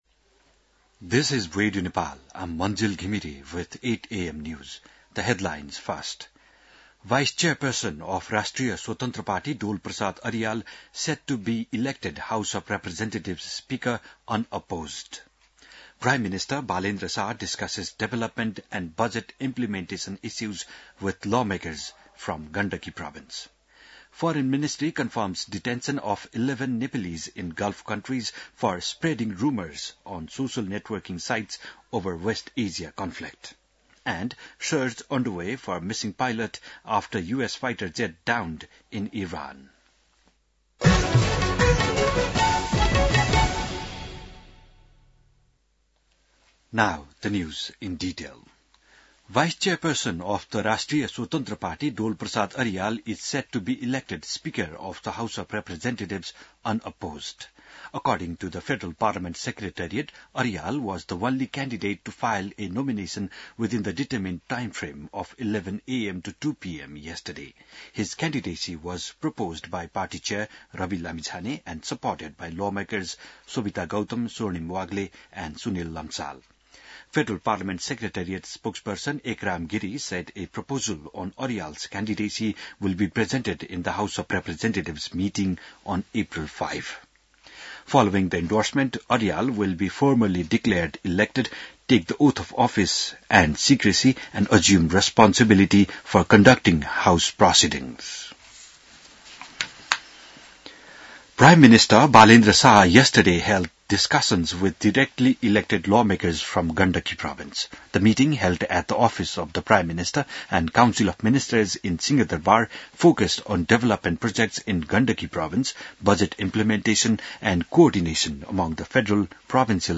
बिहान ८ बजेको अङ्ग्रेजी समाचार : २१ चैत , २०८२